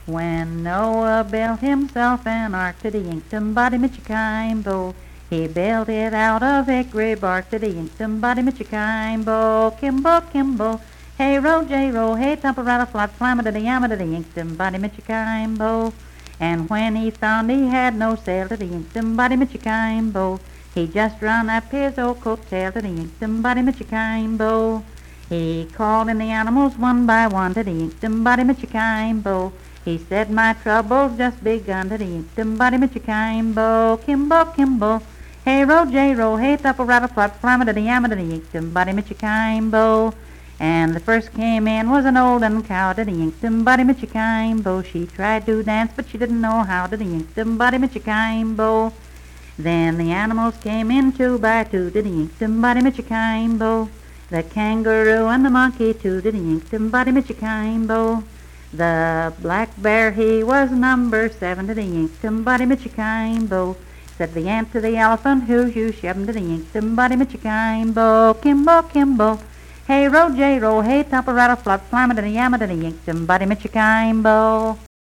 Unaccompanied vocal music performance
Verse-refrain 6(4w/R) & R(3).
Voice (sung)